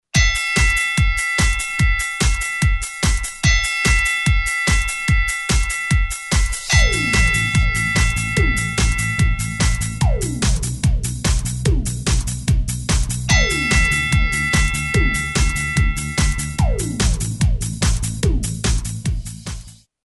No words, just easy to hear boxing bells.
The "previews" have music background for reference.
BOXING BELL CUE
No Spoken Words
CueCD-Boxing.mp3